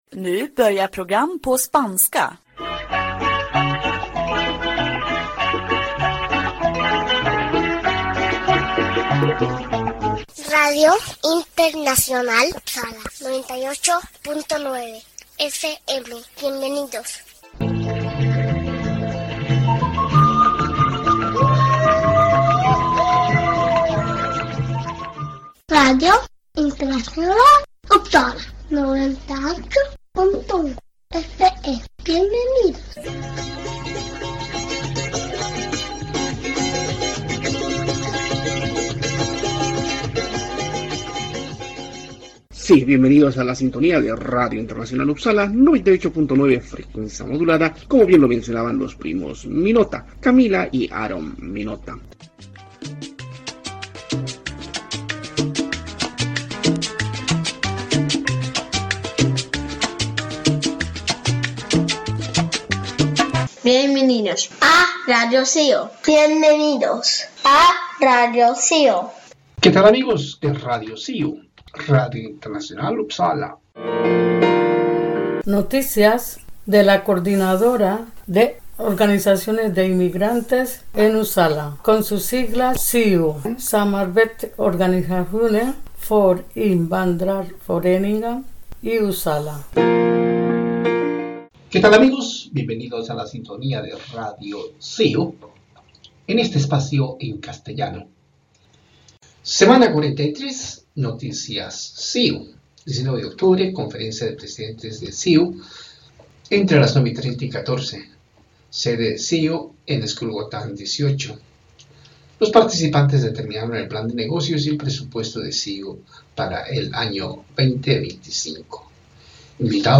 Sí, radio de cercanías en Uppsala se emite domingo a domingo a horas 18:30.